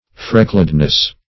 Freckledness \Frec"kled*ness\ (-k'ld*n[e^]s), n. The state of being freckled.